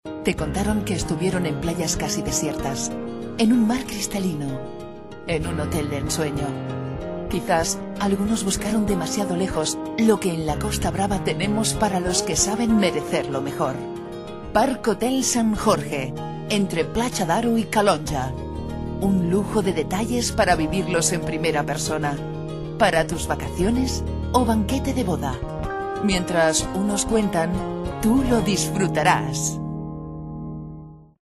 Bilingüe español-catalan; voz elegante media; mujer media; locutora española; locutora catalana; Spanish voiceover
Sprechprobe: Werbung (Muttersprache):